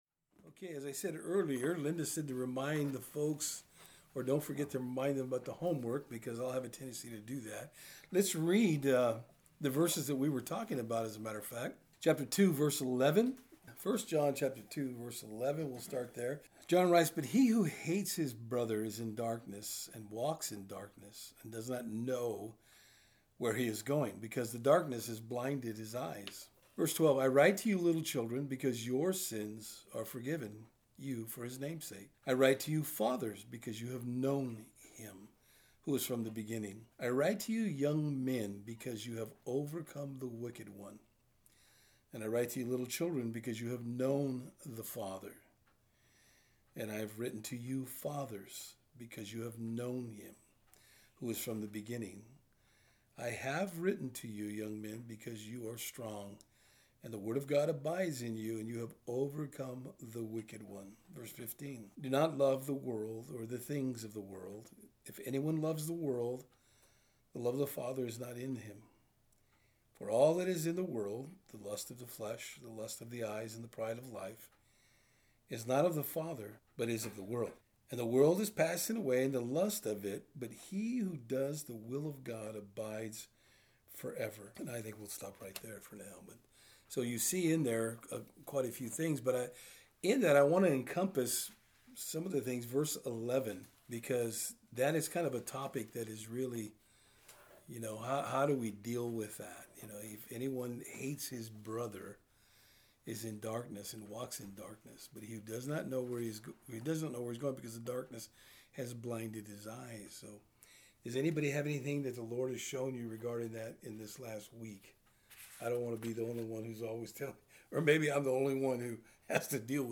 Thursday Eveing Studies The Apostle John admonishes the believers to continue to love one another.